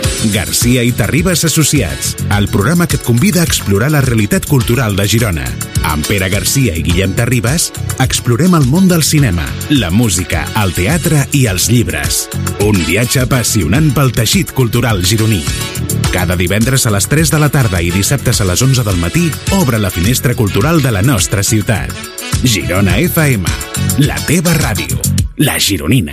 Promoció del programa.